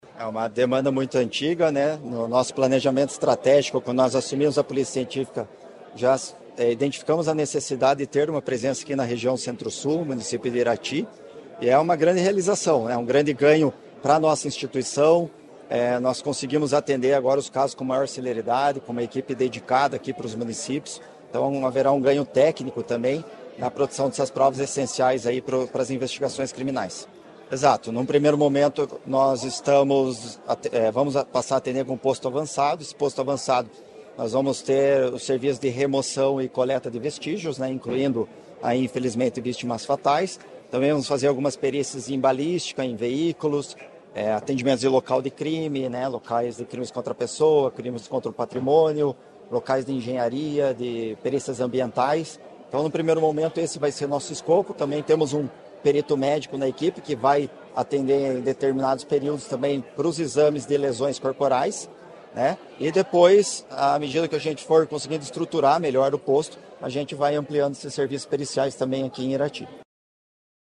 Sonora do diretor-geral da Polícia Científica, Ciro Pimenta, sobre o novo Posto Avançado em Irati